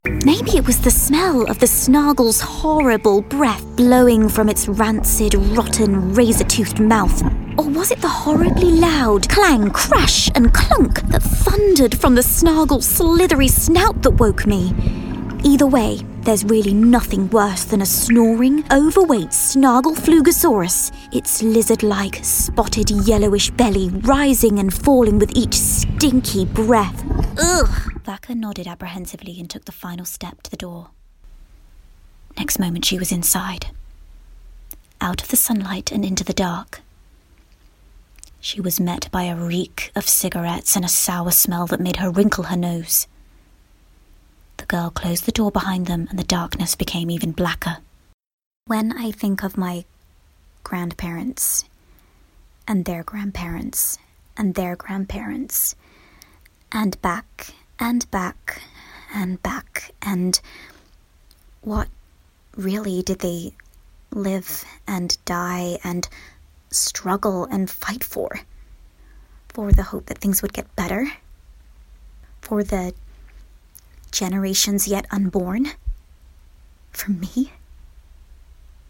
Audiobook Reel
• Native Accent: Standard English
A naturally youthful quality gives her access to a variety of teen voices, making her an easy choice for animation and video games.